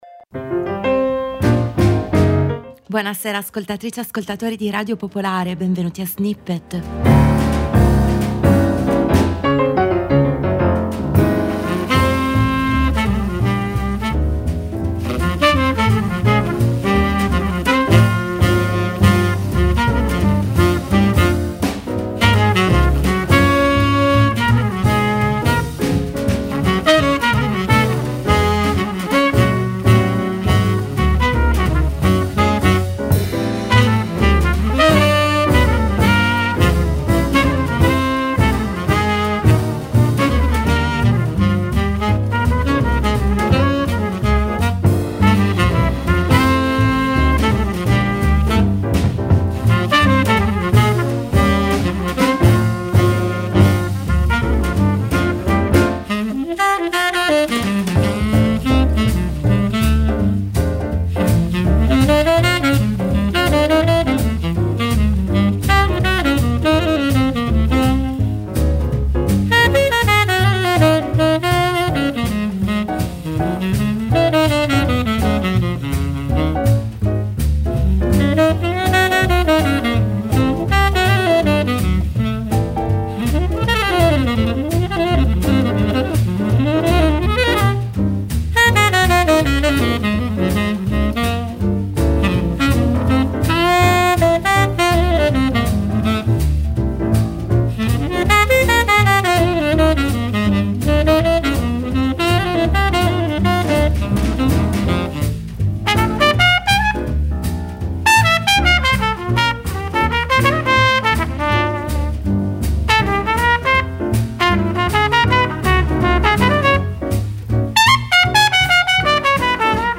attraverso remix, campioni, sample, cover, edit, mash up.